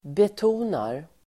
Uttal: [bet'o:nar]